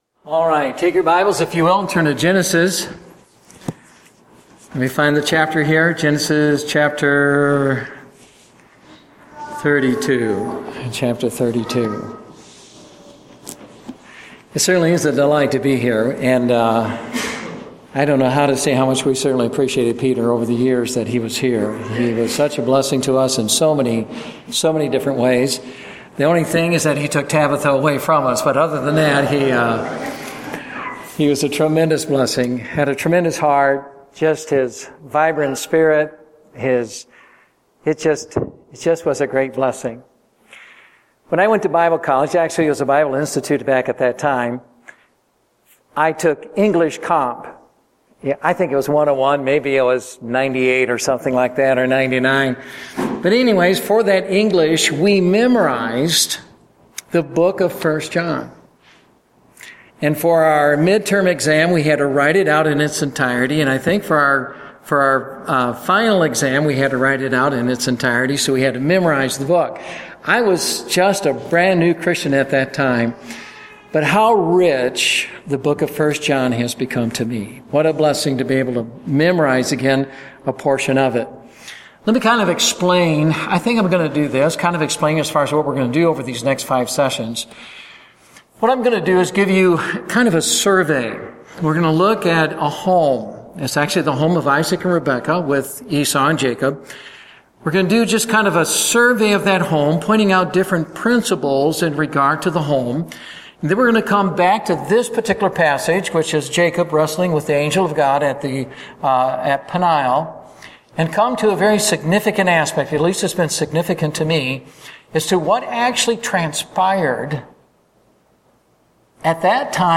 Date: August 21, 2014 (Family Camp)